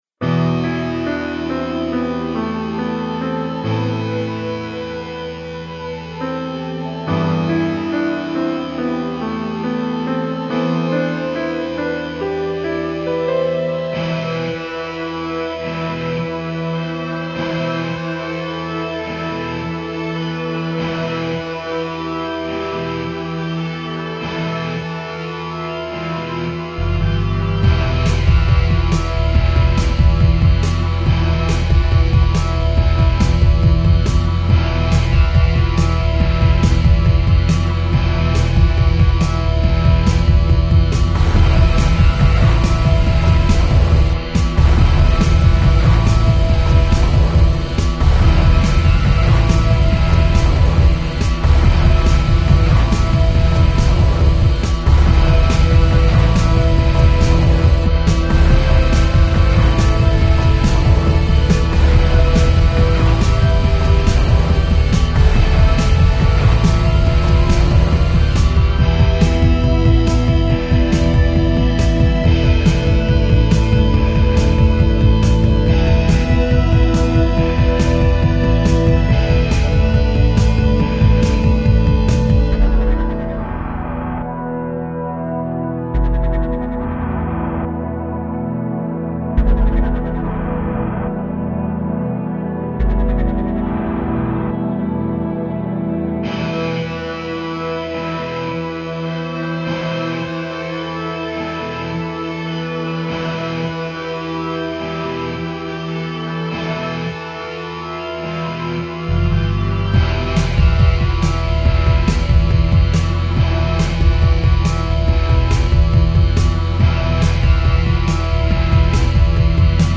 Heavyish Tech